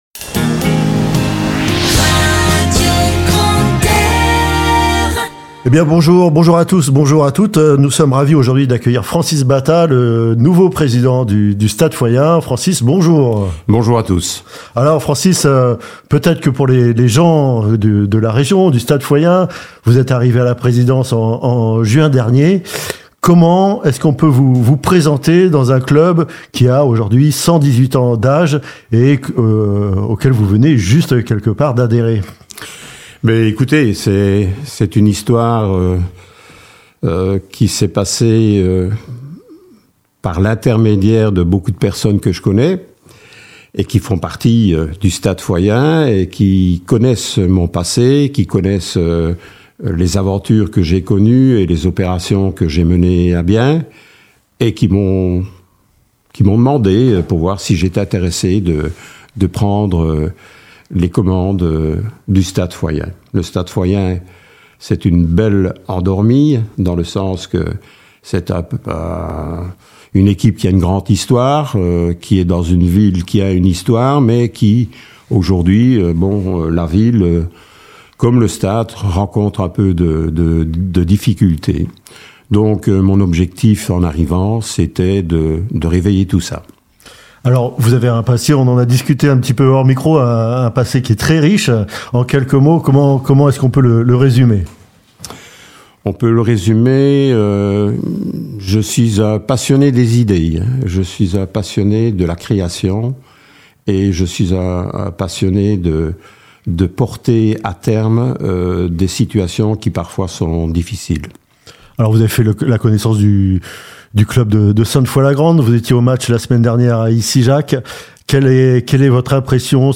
Le podcast des invités de Radio Grand "R" !